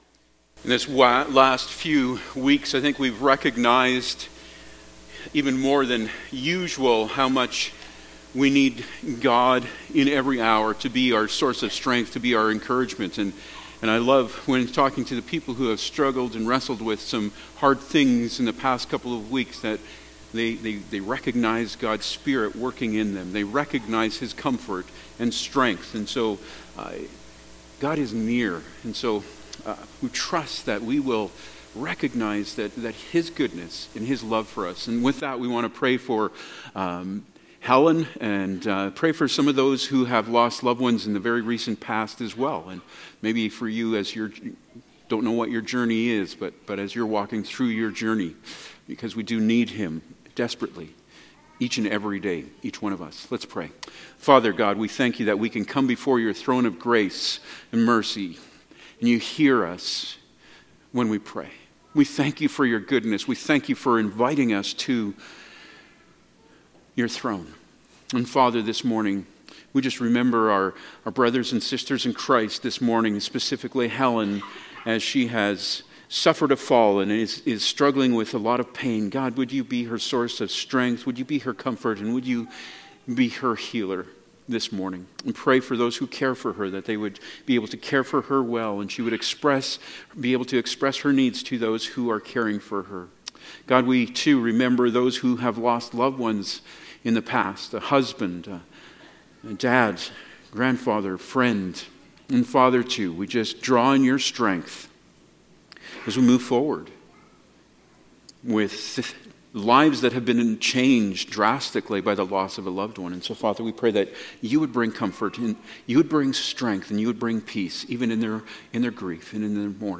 Service Type: Sunday Morning Topics: Holy Spirit